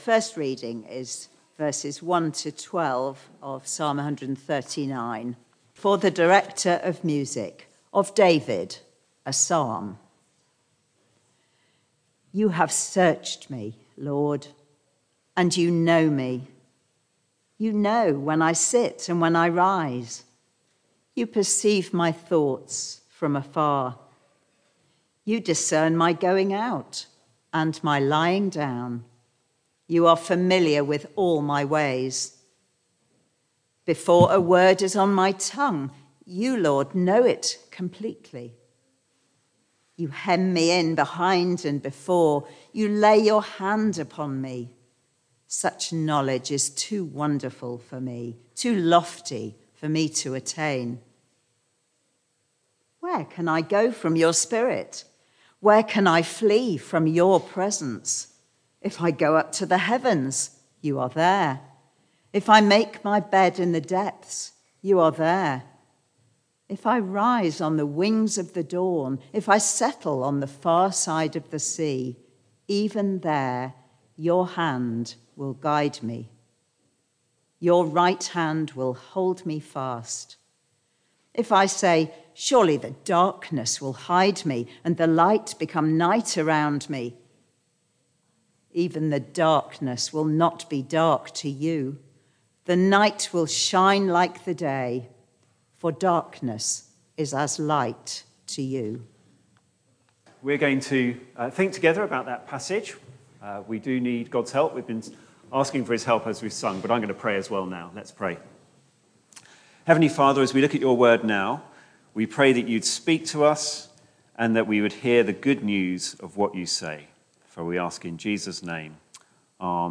Media for Barkham Morning Service on Sun 07th Jan 2024 10:00
Theme: Sermon